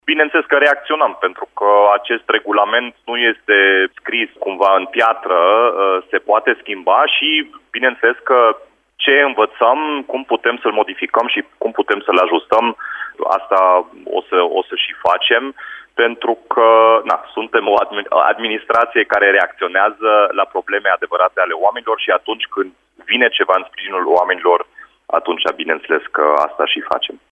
Municipalitatea a decis că persoanele fizice pot parca în zona în care locuiesc chiar și dacă au mașini de firmă. Ajustarea vine în urma problemelor constatate de specialiștii Primăriei, a declarat, la Radio Timișoara, edilul Dominic Fritz.
Dominic-Fritz-Timpark-1.mp3